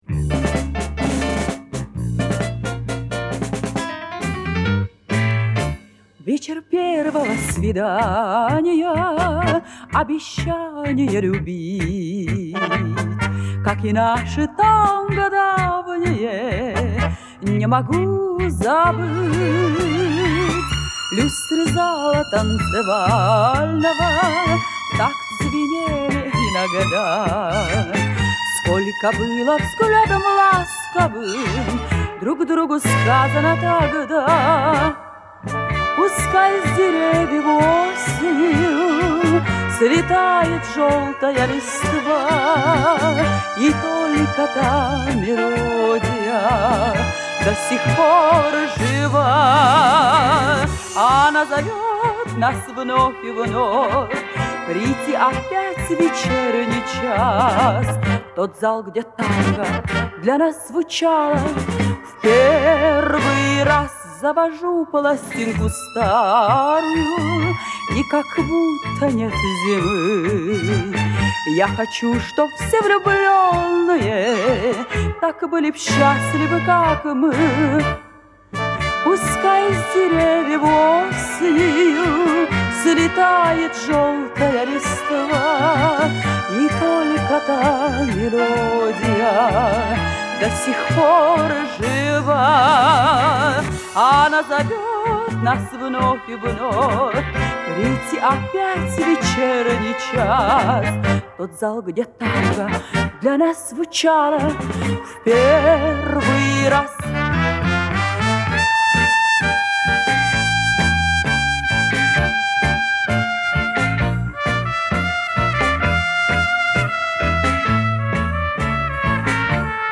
Просьба определить солистку.